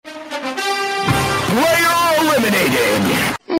Player Deaded Player Deaded sound buttons Player Deaded sound effect Player Deaded soundboard Get Ringtones Download Mp3 Notification Sound
player-eliminated-By-tuna.voicemod.net_-1.mp3